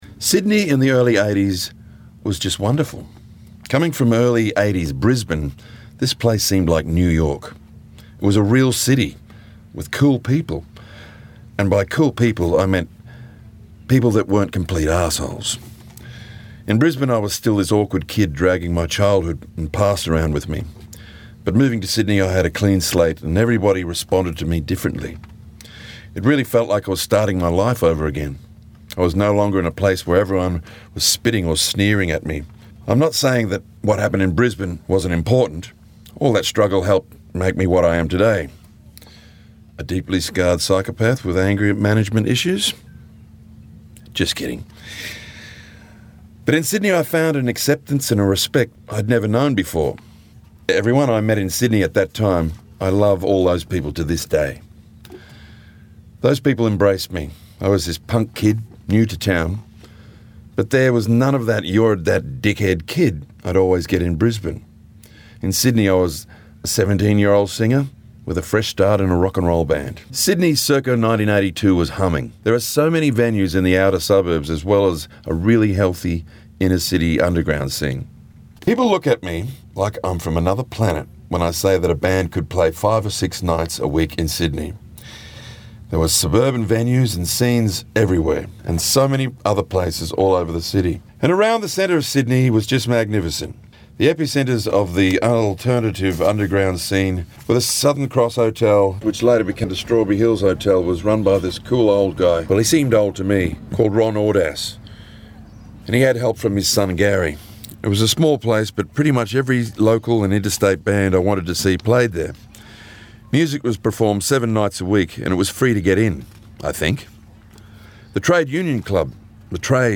Recorded at Byron writers Festival 2017
Tex-Perkins-read-edited.mp3